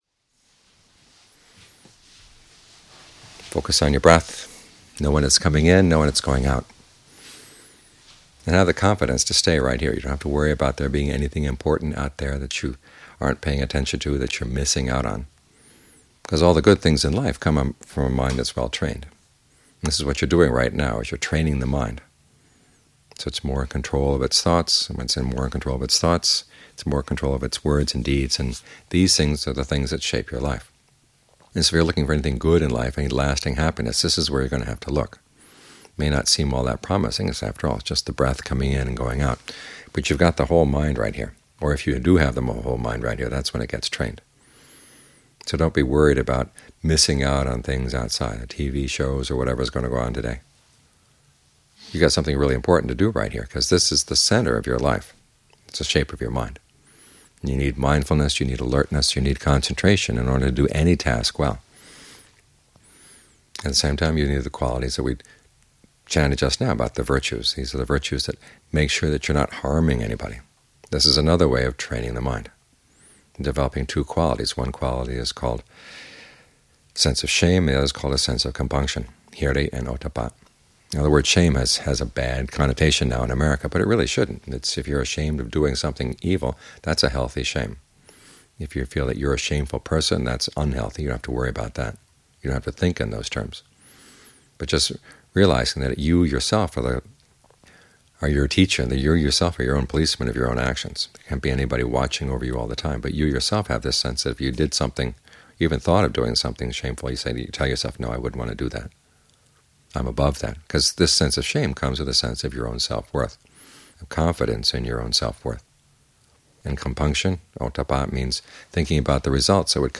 short morning talks